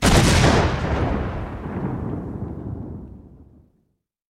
SFX枪炮声音效下载
这是一个免费素材，欢迎下载；音效素材为枪炮声， 格式为 mp3，大小1 MB，源文件无水印干扰，欢迎使用国外素材网。